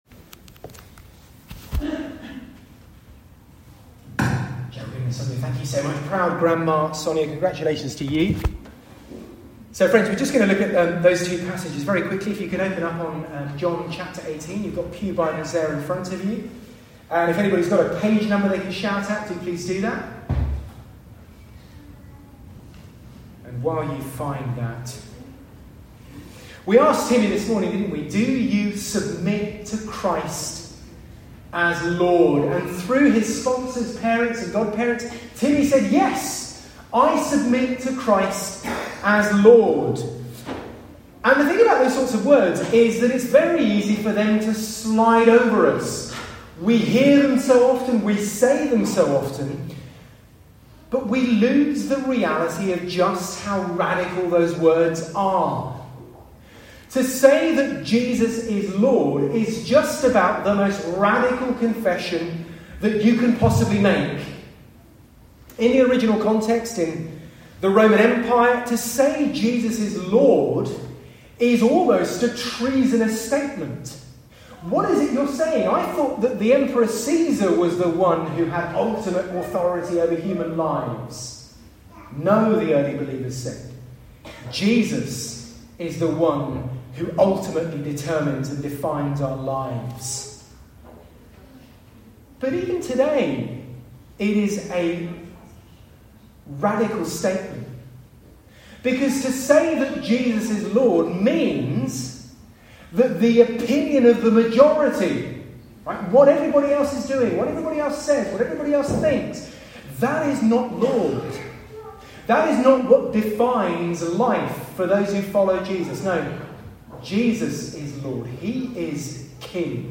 SERMON-24TH-NOVEMBER.mp3